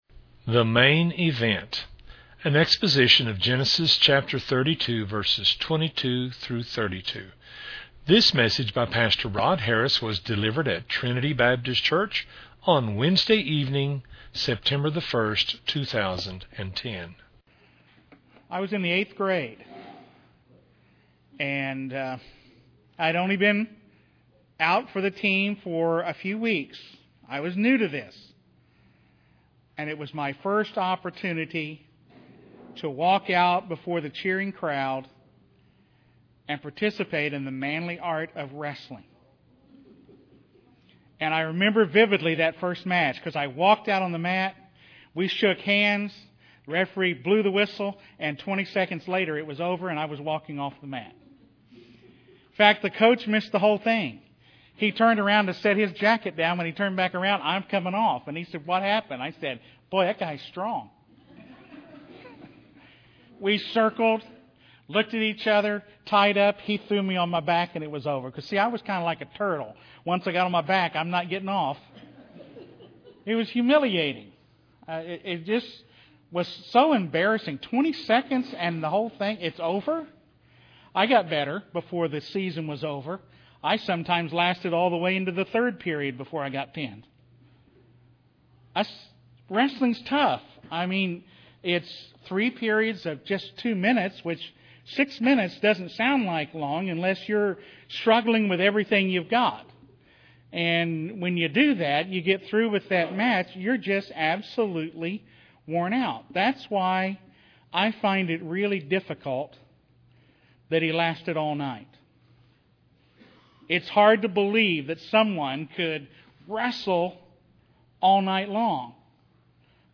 A Wednesday-evening Bible study from Genesis 32:22-32